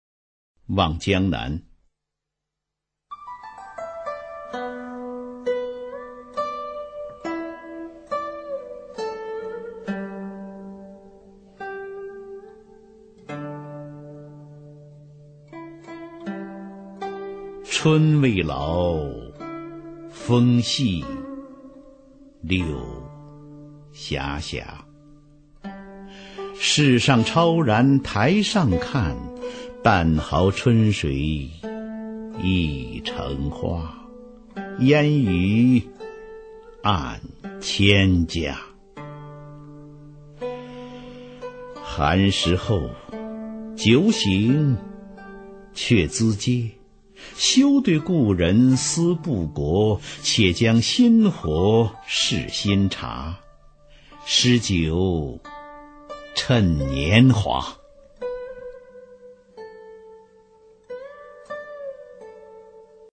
[宋代诗词诵读]苏轼-望江南 宋词朗诵